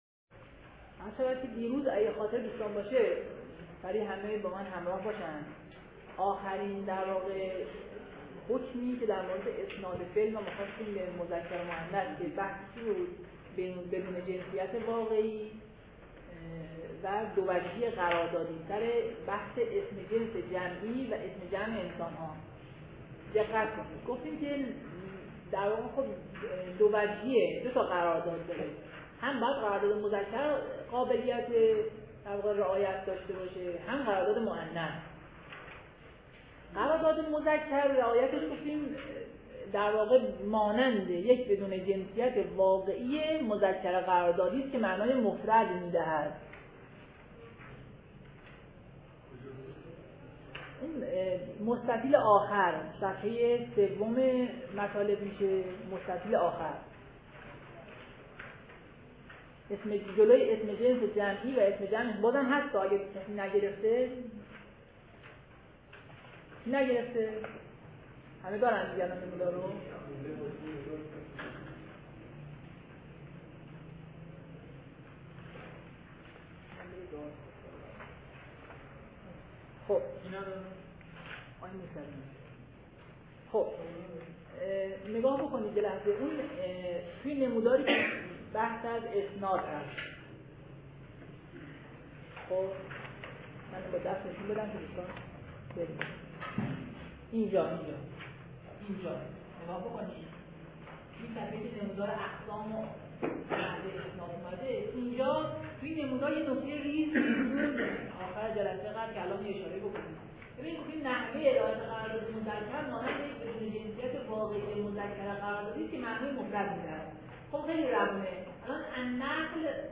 شرح صوتی مونث و مذکر و نمودار جلسه دوم.mp3